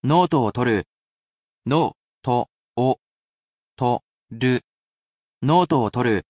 Though he’s a robot, he’s quite skilled at speaking human language. He’s lovely with tones, as well, and he will read each mora so you can spell it properly in kana.